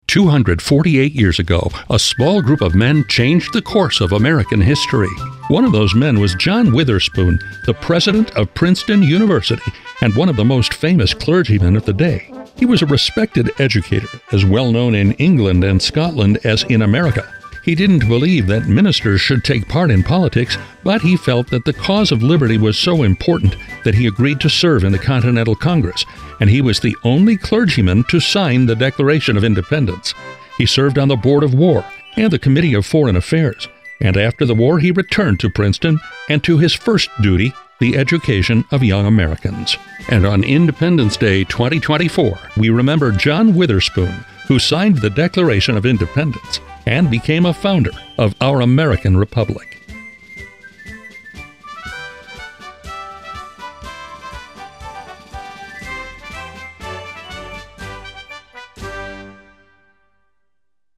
20 :60-second features
This unique series contains 60-second profiles of the men who signed the Declaration of Independence, formatted :50/:10 to allow for sponsor tag.